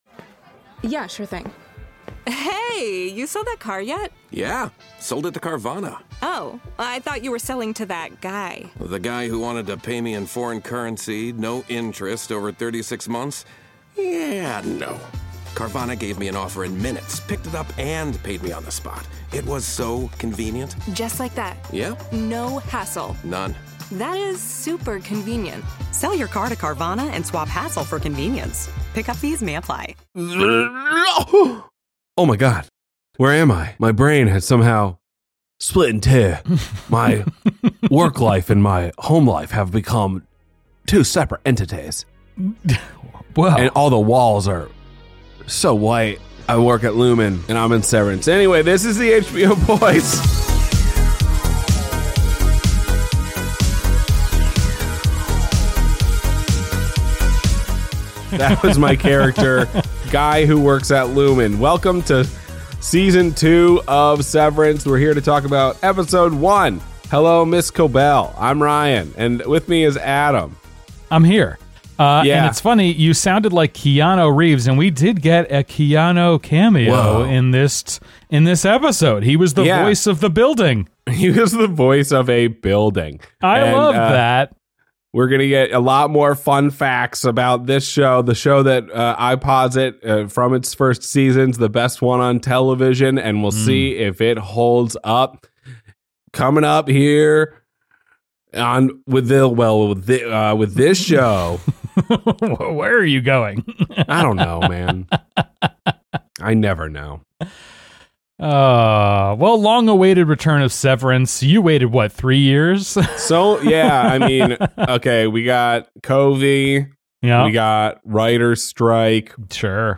Longtime friends discuss the movies and television they love.